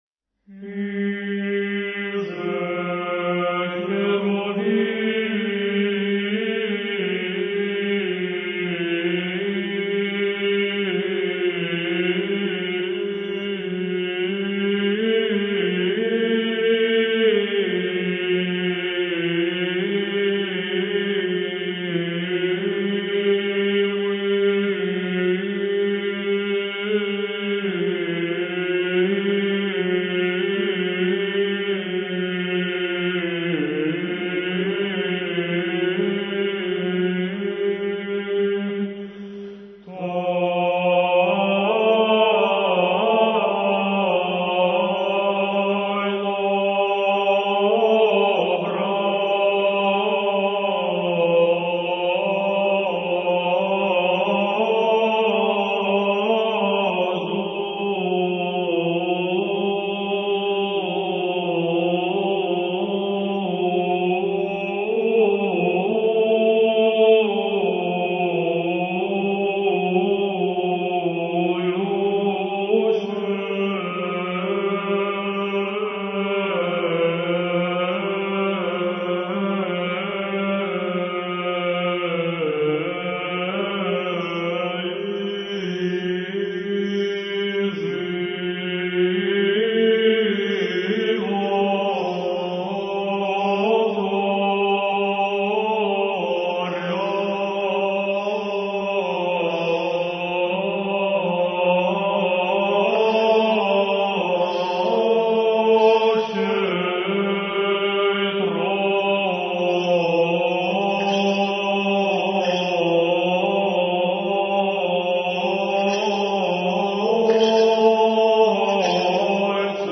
Архив mp3 / Духовная музыка / Русская / Ансамбль "Сретение" /